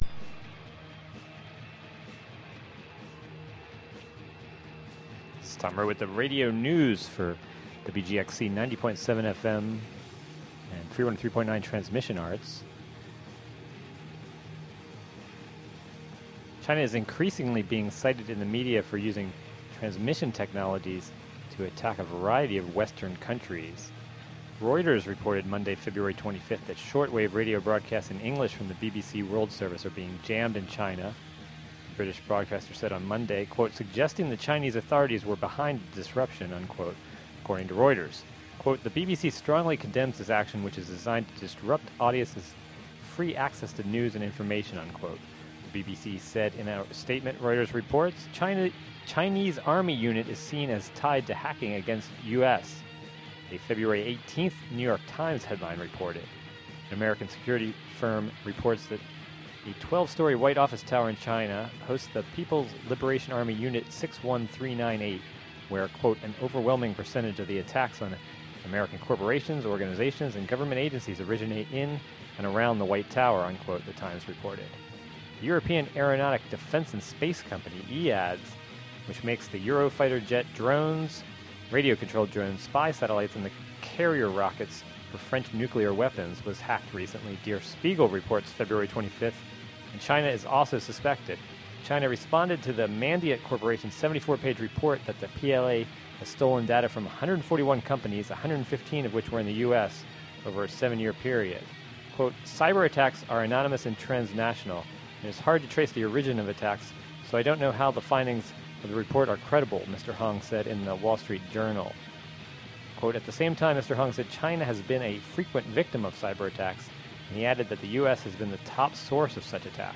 Radio News: China Airwave Attacks (Audio)